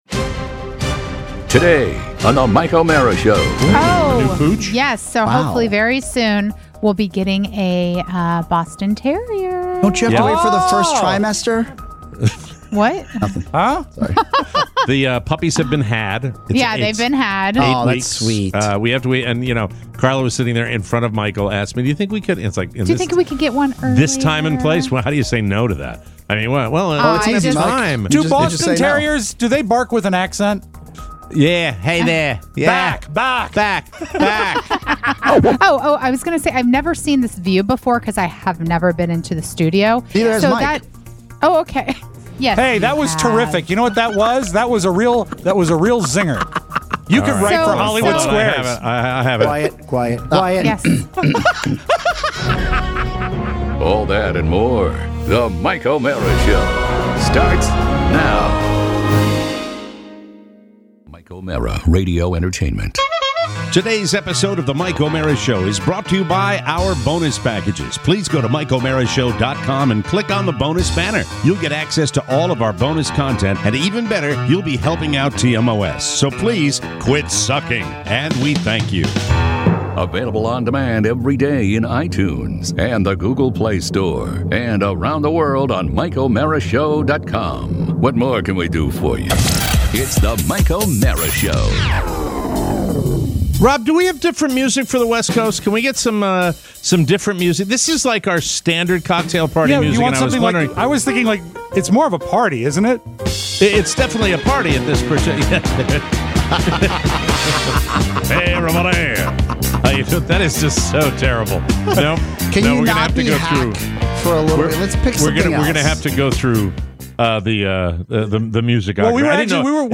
It’s a late day TMOS Cocktail Party! We’re talking NFL Draft… your calls… and the spouses are all here.